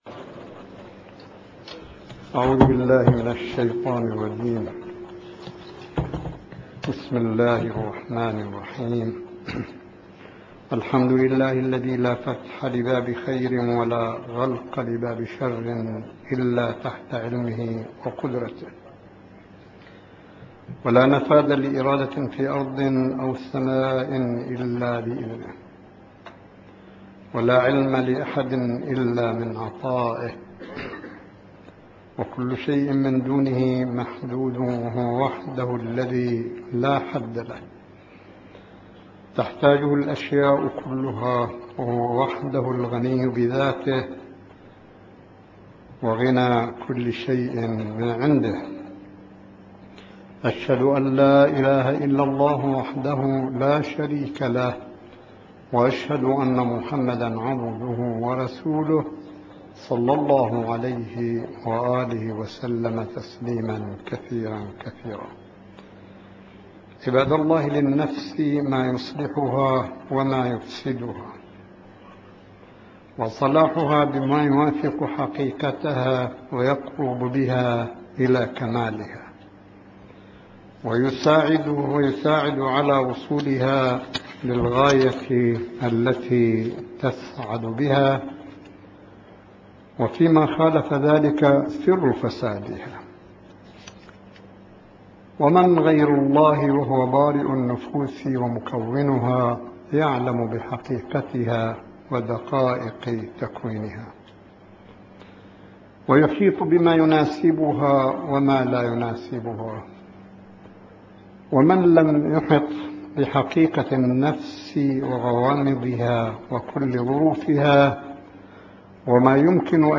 صوت: خطبة الجمعة (615) 17 شوال 1435هـ – 15 أغسطس 2014م